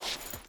Stone Chain Jump.wav